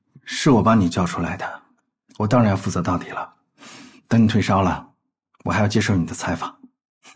Professionell VD-röst för företagsledarskap
Auktoritär AI-genererad VD-röst
Ledande ton
Inmatningsljud(klicka för att ladda ner)